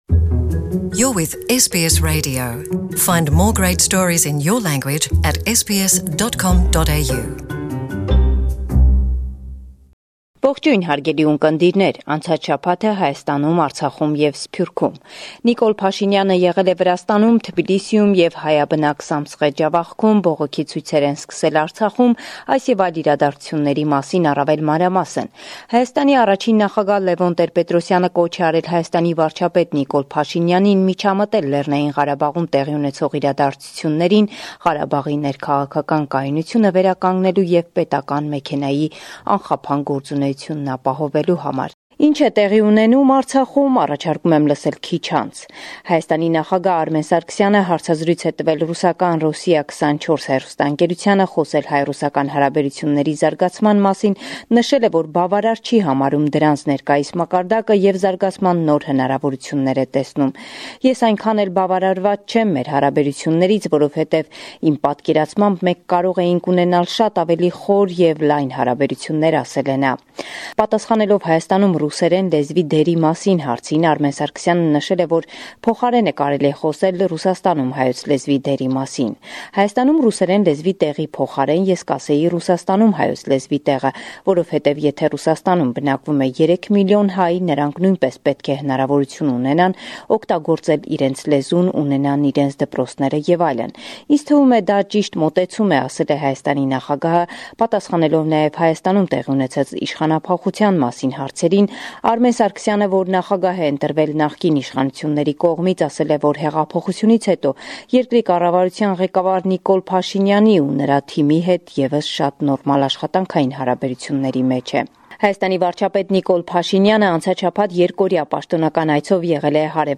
Վերջին Լուրերը – 5 Յունիս 2018